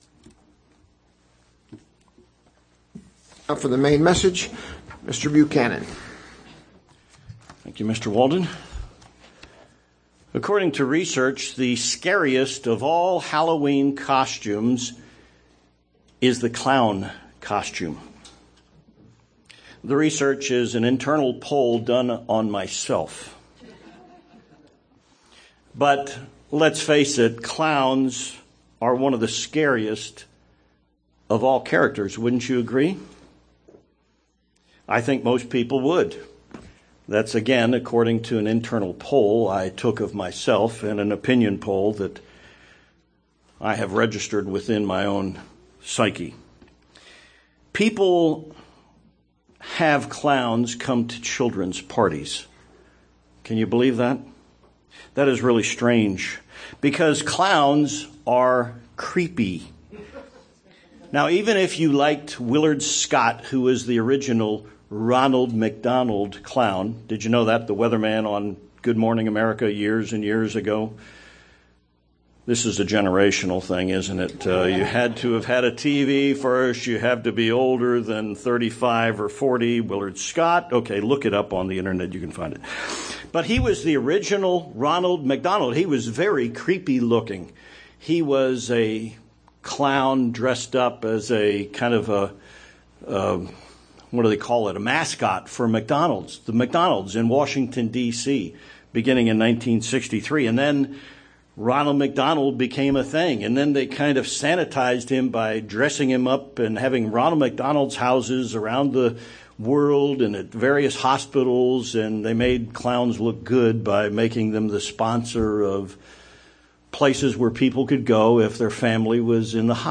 Why do we have the escalation of controversy, anxiety and conflict in the normally peaceful Western world? This sermon examines the reason Satan has had influence over man's history and why his presence will be increasing prior to Jesus' return to earth.